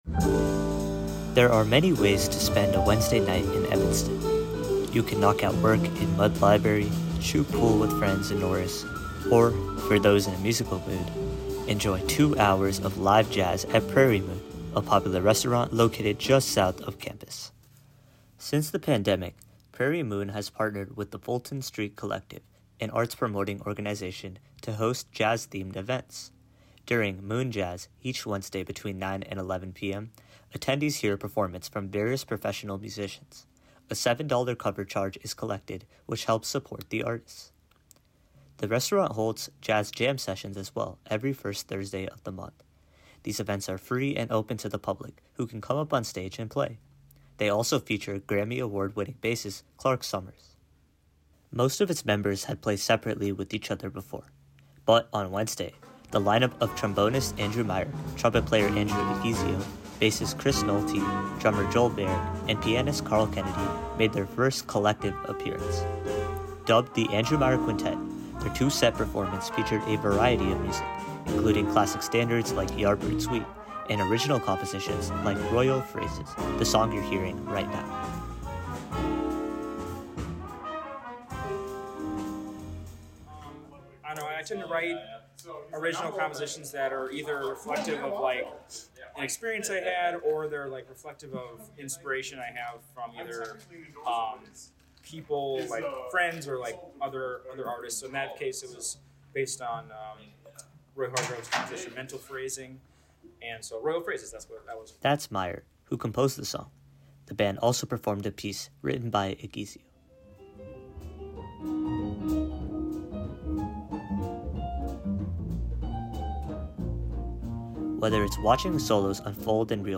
[nat sound of jazz performance, slowly fading out] There are many ways to spend a Wednesday night in Evanston.